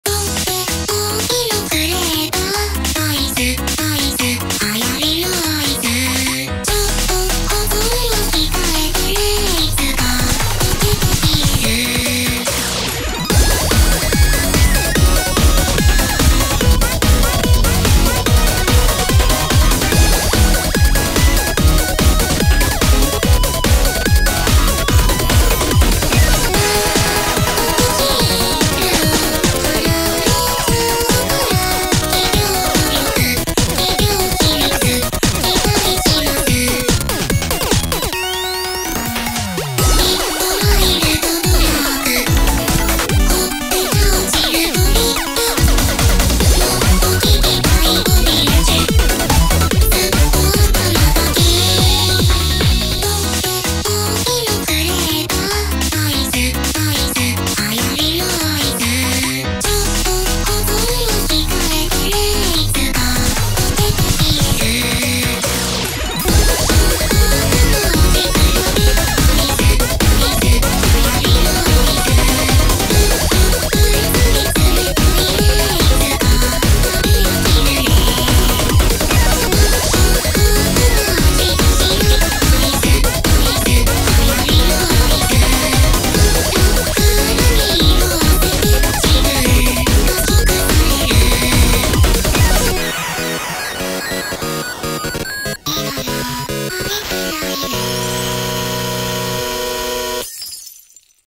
BPM145
Audio QualityCut From Video